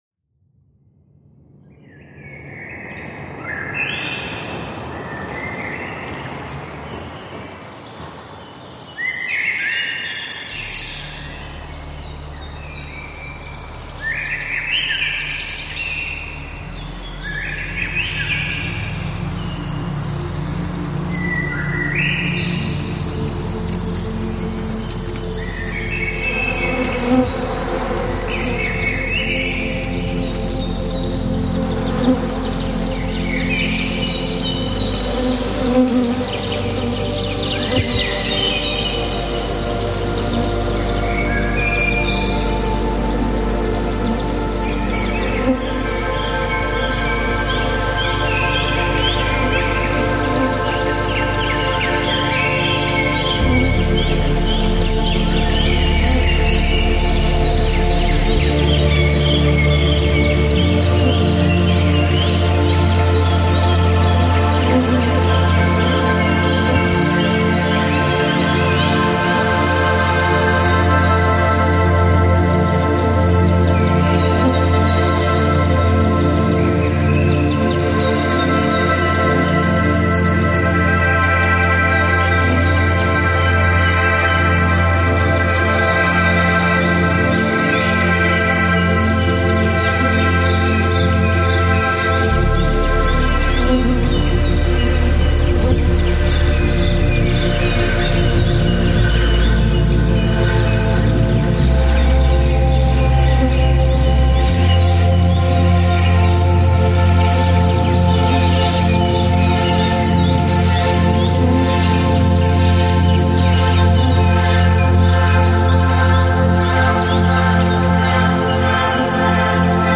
Lege dich in die Frequenzen und heile.